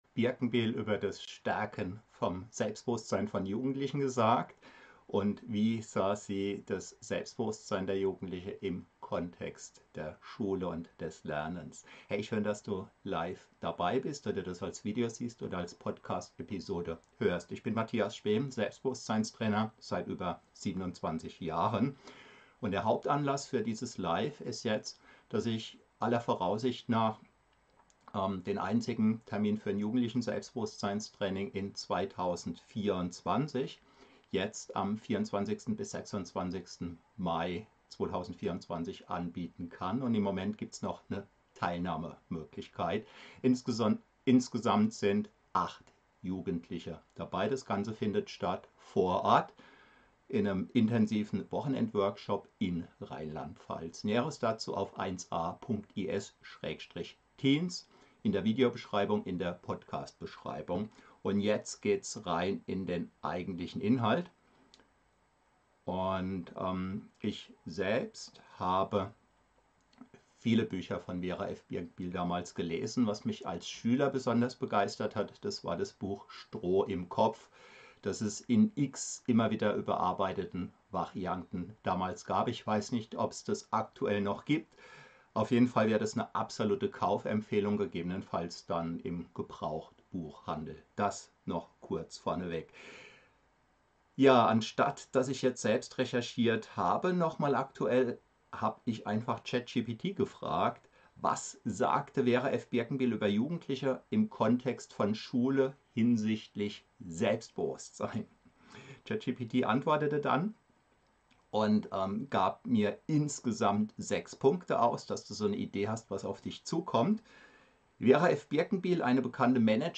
In diesem Livestream teile ich wertvolle Einblicke aus meiner 27-jährigen Praxis als Selbstbewusstseinstrainer und gebe praktische Empfehlungen, wie Jugendliche ihr Selbstbewusstsein stärken können. Das Wesentliche: Tipps und Erfahrungen für starkes Selbstbewusstsein bei Jugendlichen.